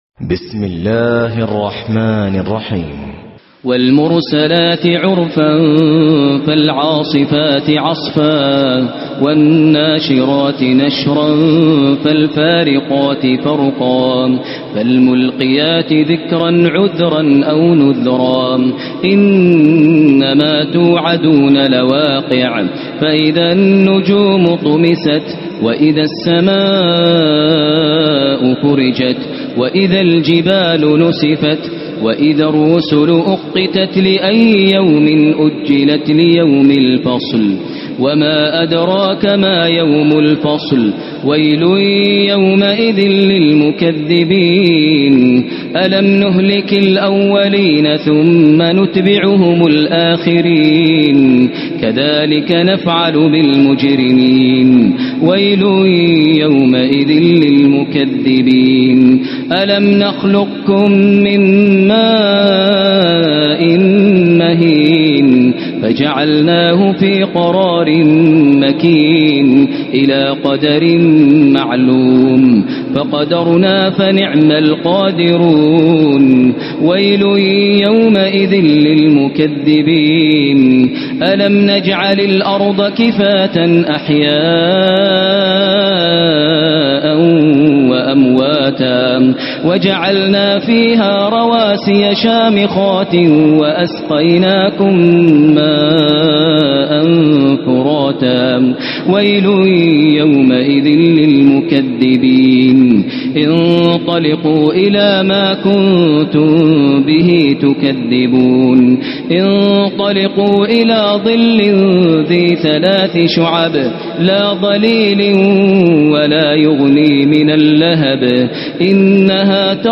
تلاوات
المرسلات القارئ: فضيلة الشيخ مجموعة مشائخ وعلماء الصنف: تلاوات تاريخ: السبت 24 رمضان 1436 هـ الموافق لـ : 11 جويلية 2015 م رواية : حفص عن عاصم الحجم:1.2M المدة :00:05:20 حمله :105 سمعه :245 سماع التلاوة تحميل التلاوة